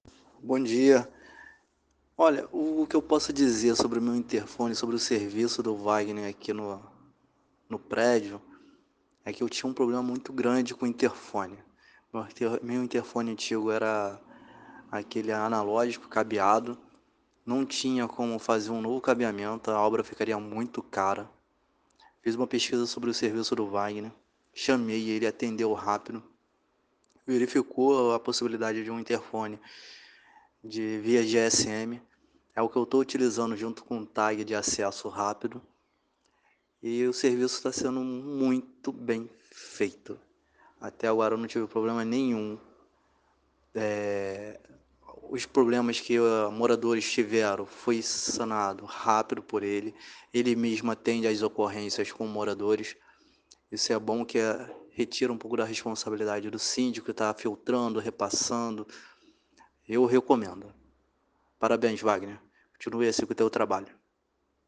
DEPOIMENTO DE VOZ DE CLIENTES